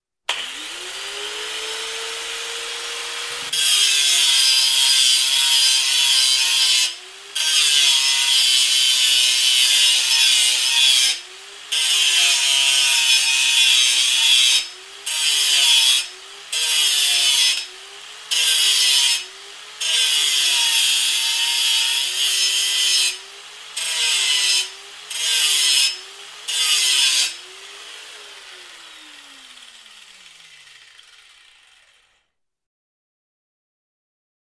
На этой странице собраны реалистичные звуки работающих станков: токарных, фрезерных, шлифовальных и других.
Звук работающей болгарки